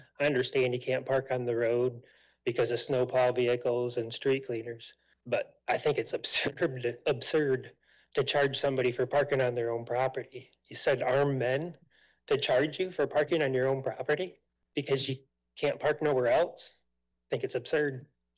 Audience member